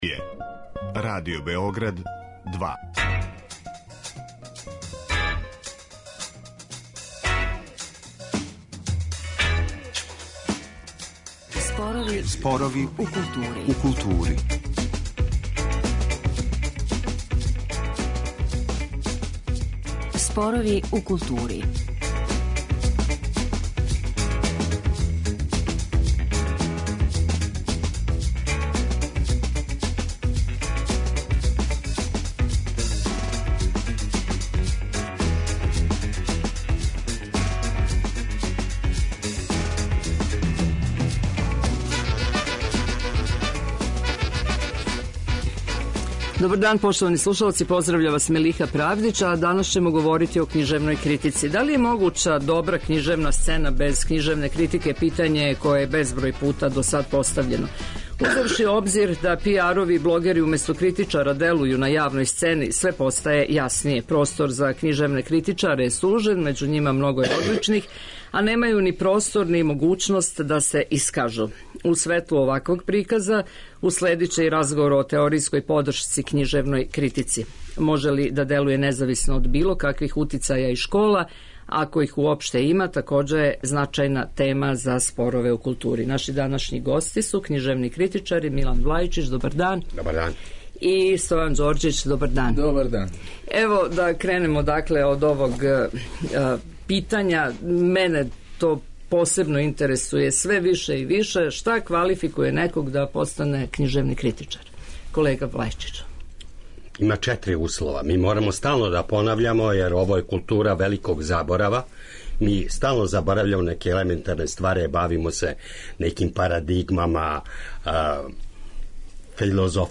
У светлу оваквог приказа уследиће и разговор о теоријској подршци.